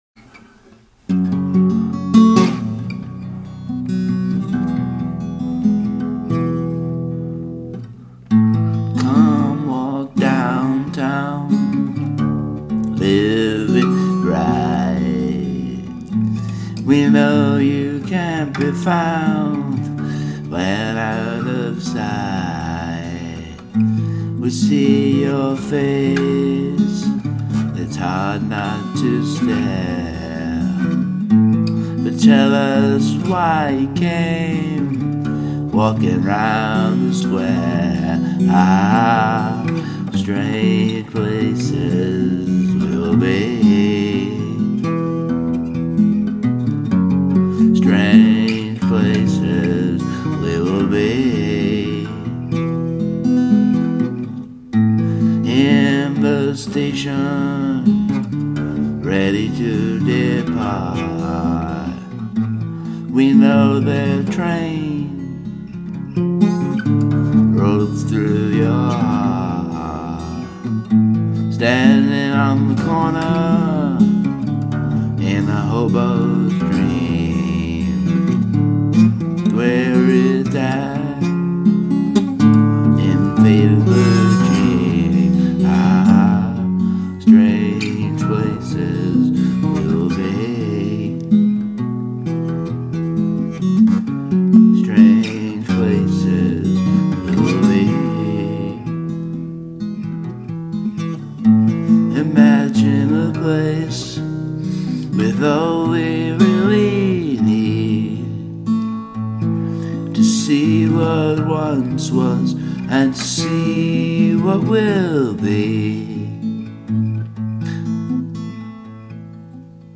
Alternative Rock